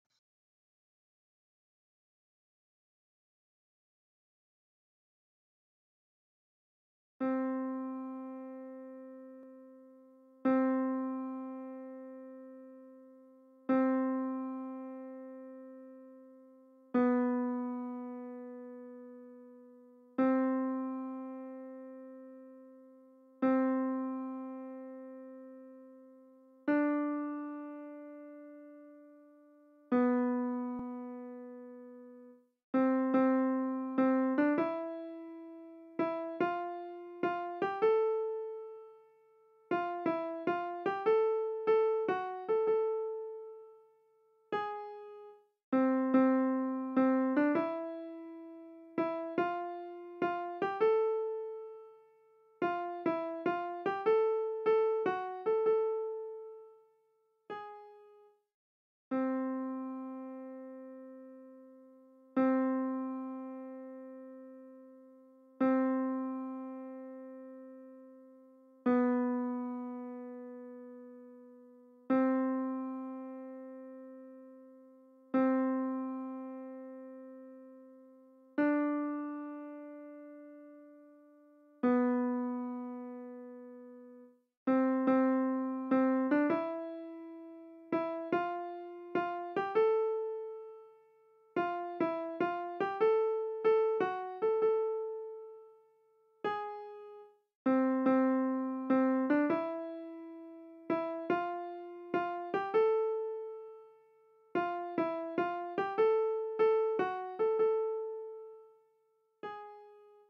Répétition SATB par voix
ALTI
Regarde l'Etoile. Voix Alto.mp3